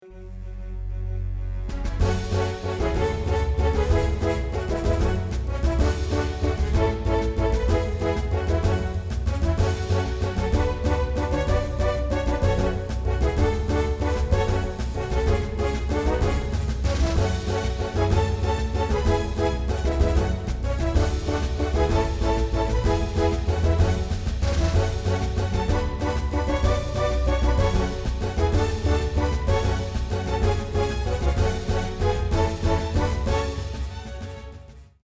Genre film / musical
• instrumentatie: Viool